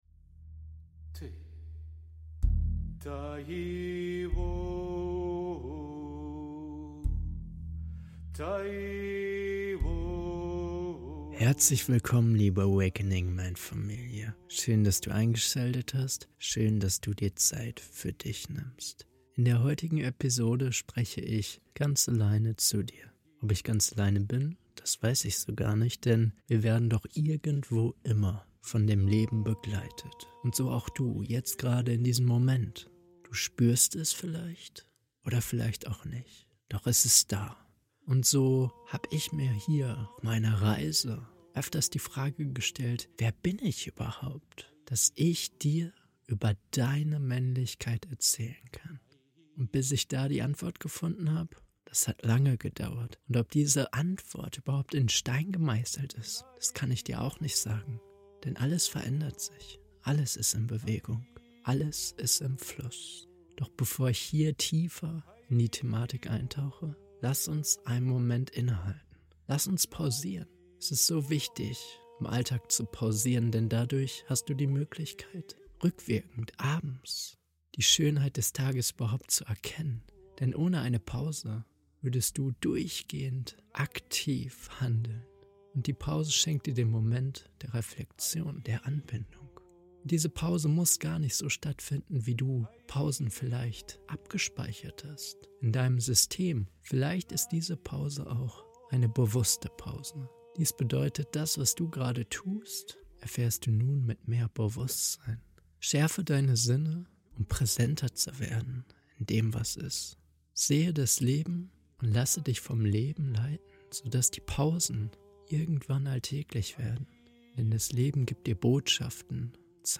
In der aktuellen Solo-Folge lade ich dich dazu ein, dich an die Essenz deiner eigenen männlichen Identität zu erinnern.